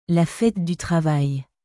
La fête du travailラ フェトゥ デュ トラヴァイユ